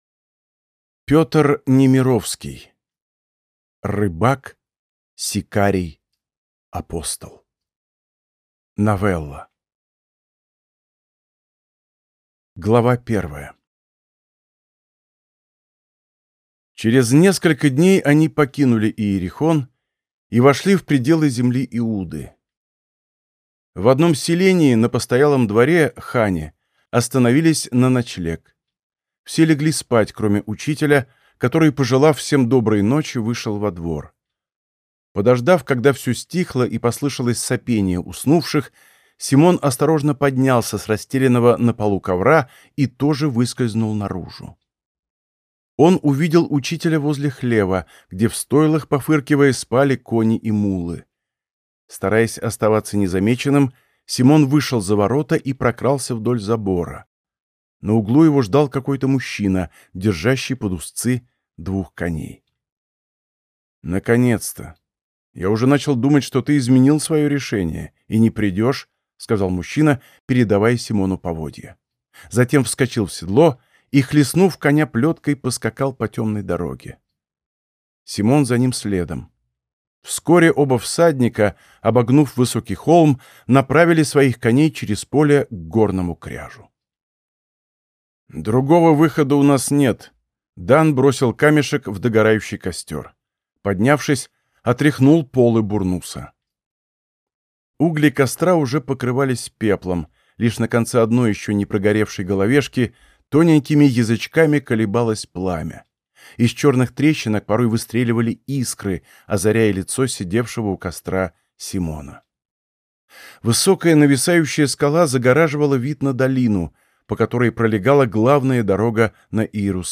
Аудиокнига Рыбак, сикарий, апостол | Библиотека аудиокниг
Прослушать и бесплатно скачать фрагмент аудиокниги